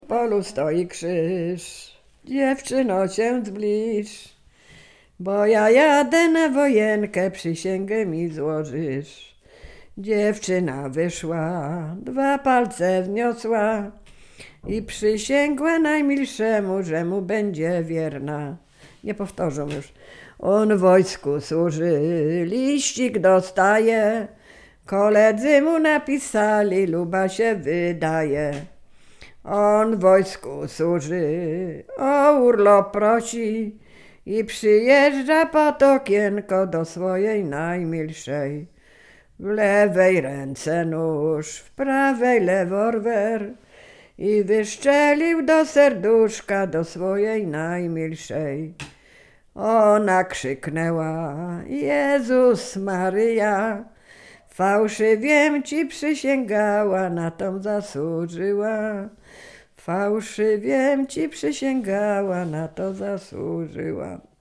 Sieradzkie
liryczne miłosne pieśni piękne